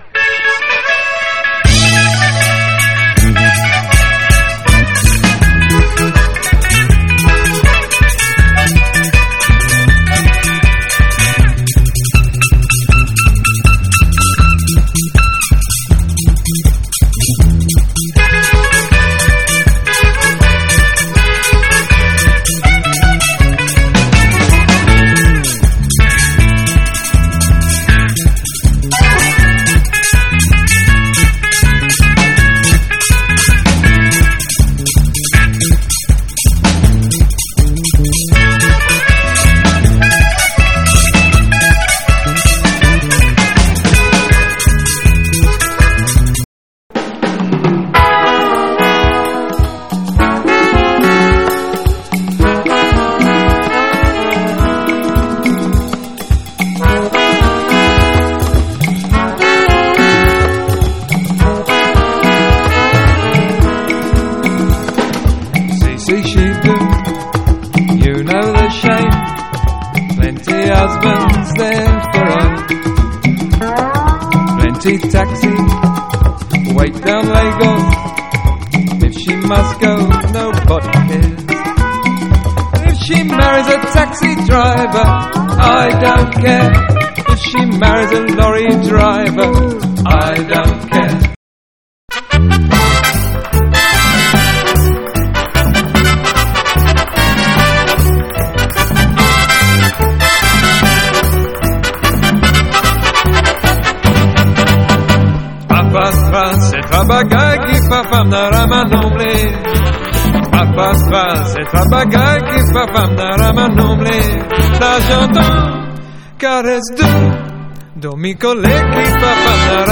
ROCK / 70'S / COSMIC / BALEARIC